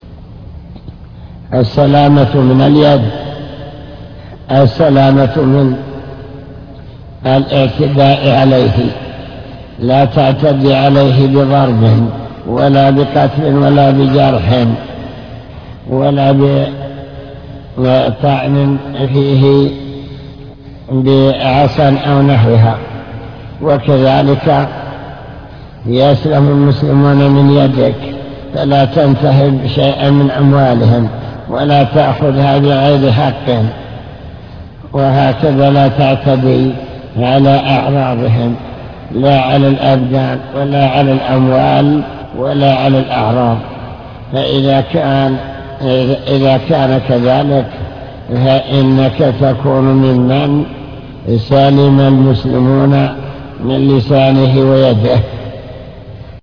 المكتبة الصوتية  تسجيلات - كتب  شرح كتاب بهجة قلوب الأبرار لابن السعدي شرح حديث المسلم من سلم المسلمون